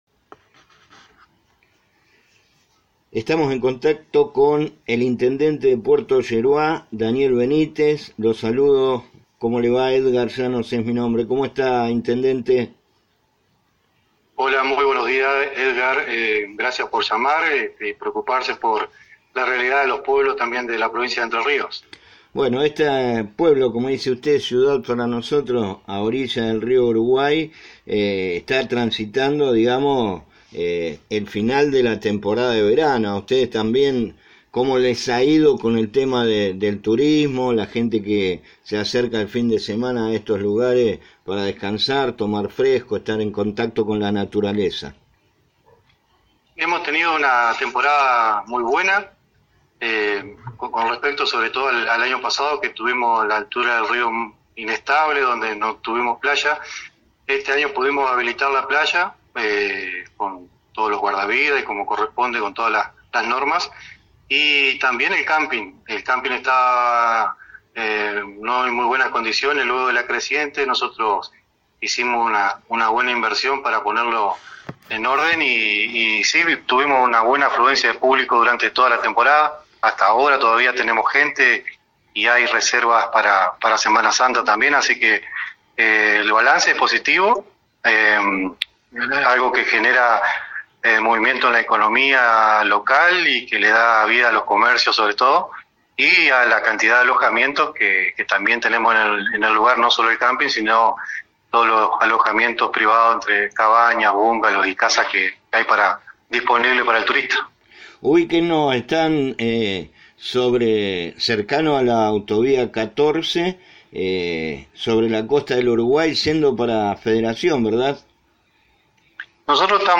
Hablamos con el Intendente de Puerto Yeruá, ciudad ubicada a orillas del Río Uruguay, con quien analizamos la situación económica, la falta de obra pública por parte del gobierno de Milei y la postura de la Liga de Intendentes de cara a las elecciones legislativas
Daniel-Benitez-intendente-de-Puerto-Yerua.mp3